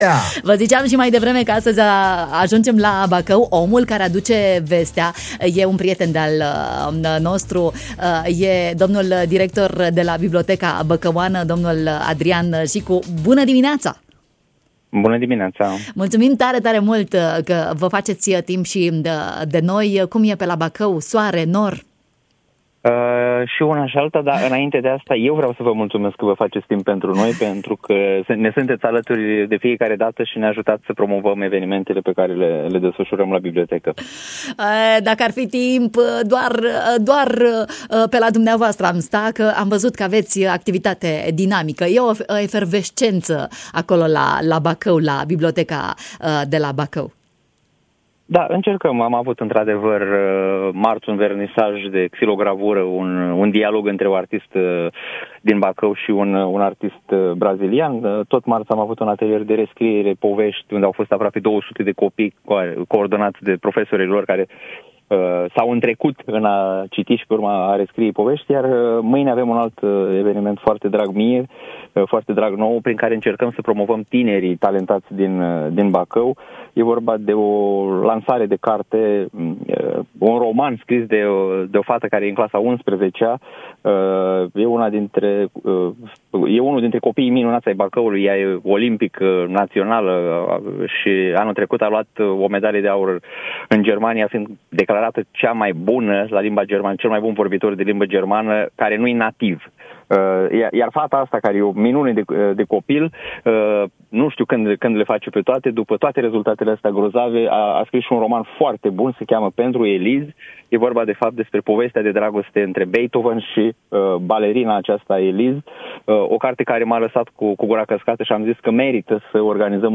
în direct, în matinal